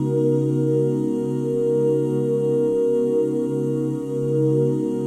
OOH C#MIN9.wav